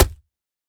Minecraft Version Minecraft Version 1.21.5 Latest Release | Latest Snapshot 1.21.5 / assets / minecraft / sounds / block / packed_mud / break4.ogg Compare With Compare With Latest Release | Latest Snapshot